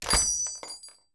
shop_button.wav